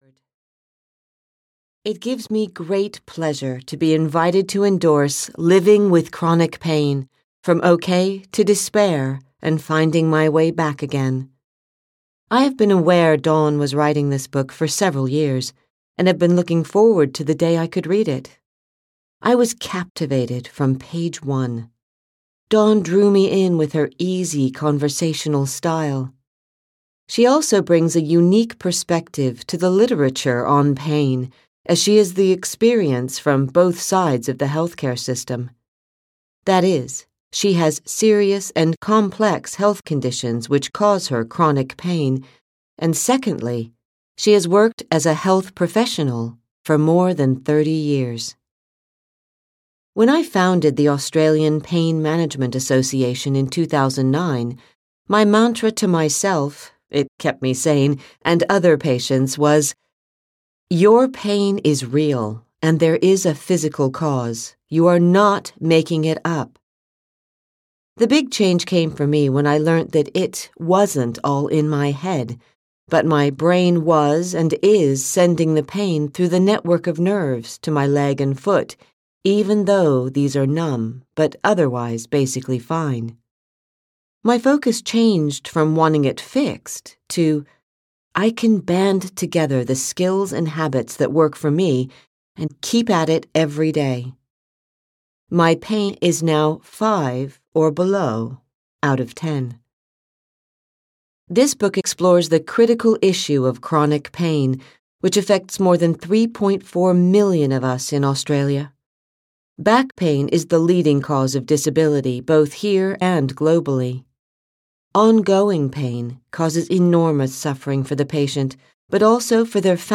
Audio knihaLiving with Chronic Pain: From OK to Despair and Finding My Way Back Again (EN)
Ukázka z knihy